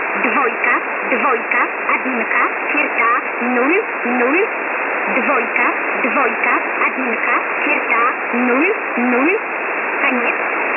168 Кб 01.03.2014 14:30 Номерная станция на 5815 кГц.